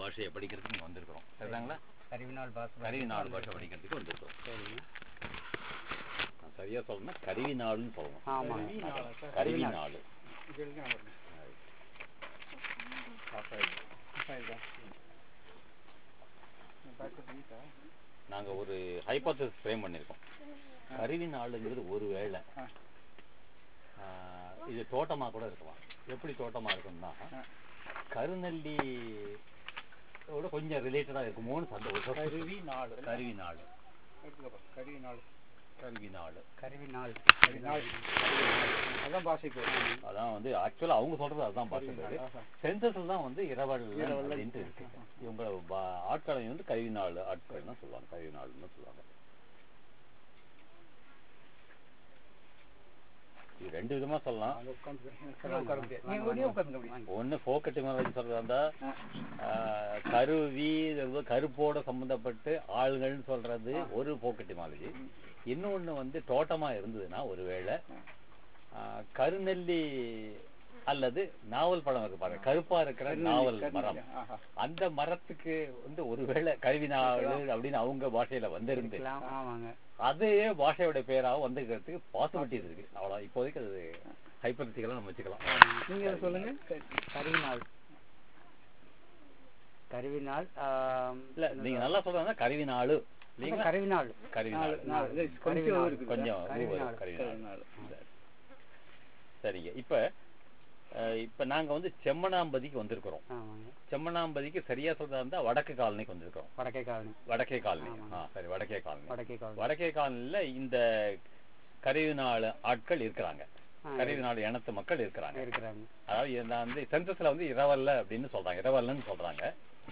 Conversation about the consultant's background